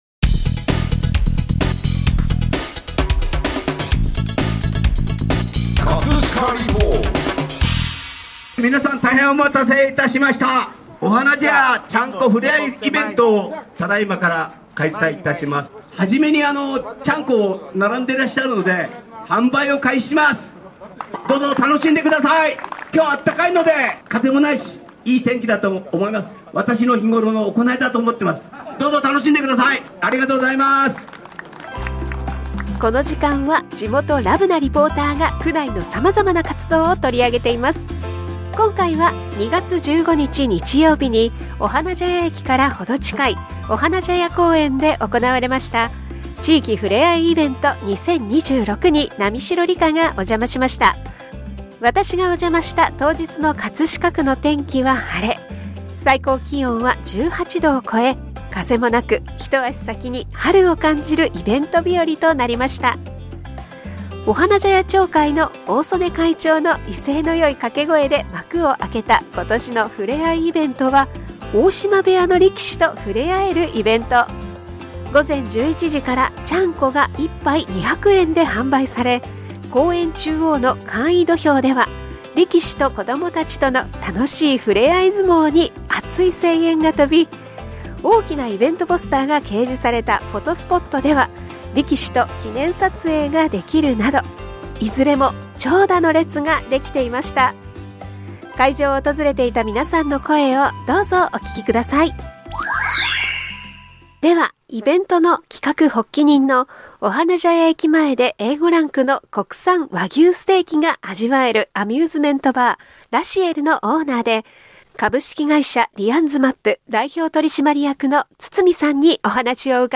【葛飾リポート】 葛飾リポートでは、区内の様々な活動を取り上げています。
会場を訪れていた皆さんの声をどうぞお聴きください。